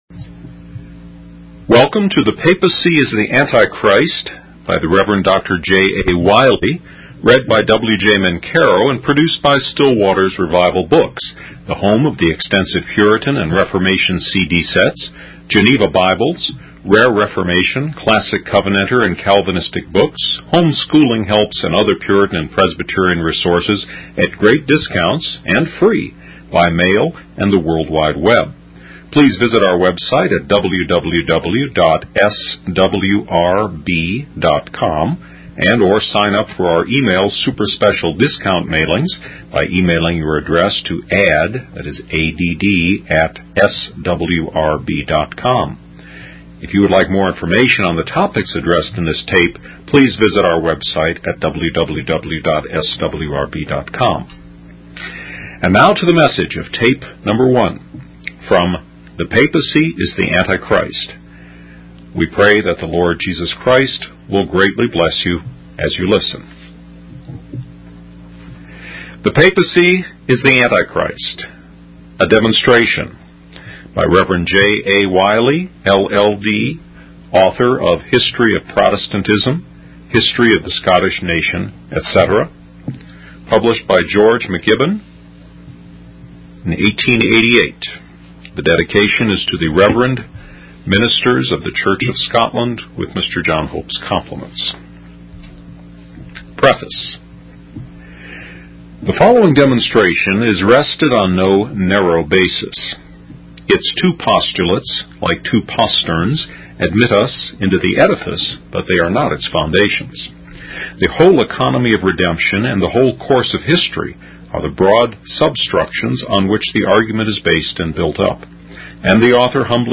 The sermon transcript discusses the vision of the doubling of prophecies, indicating their certainty. The apostle Paul is portrayed as the interpreter of these prophecies, revealing the satanic nature of a power that was approaching during his time.